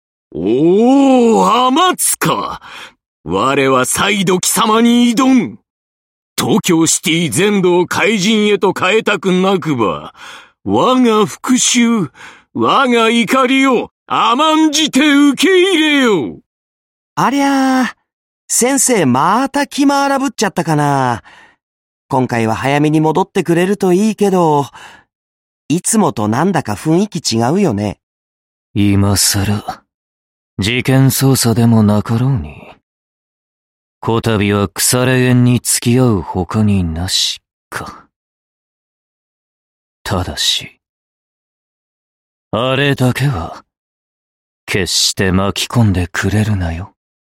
声优 关俊彦&关智一&岛崎信长